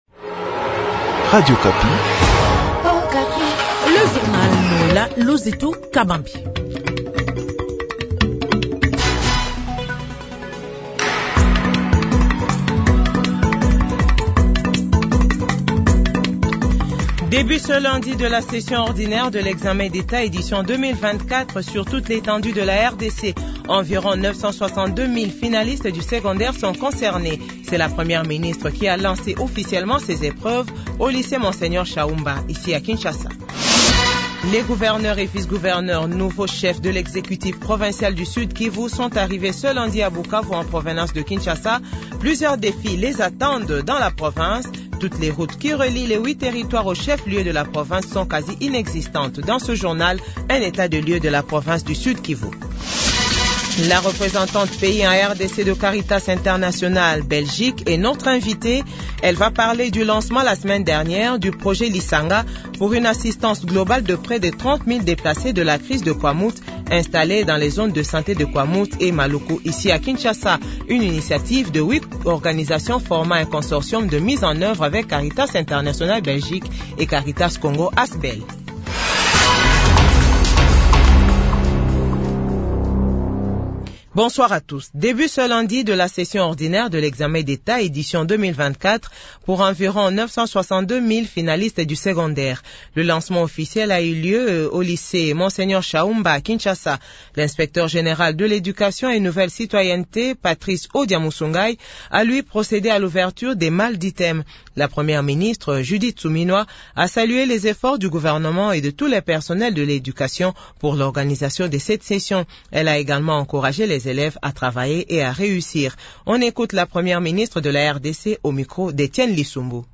JOURNAL FRANÇAIS 18H00